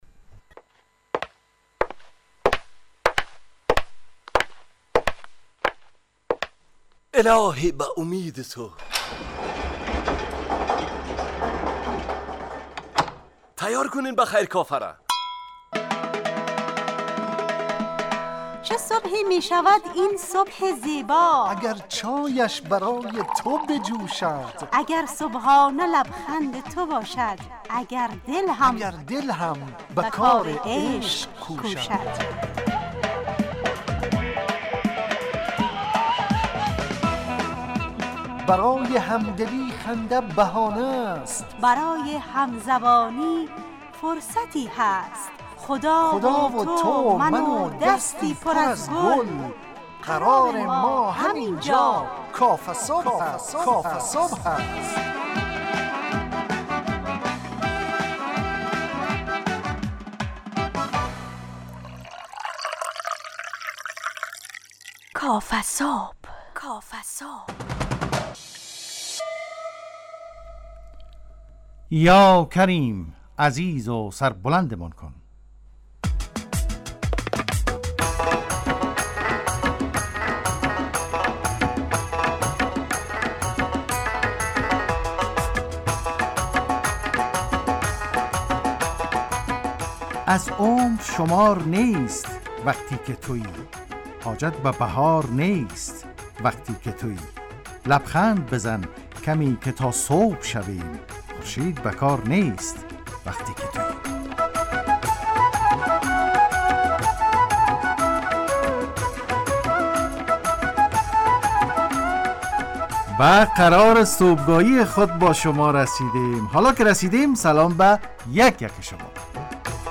کافه‌صبح – مجله‌ی صبحگاهی رادیو دری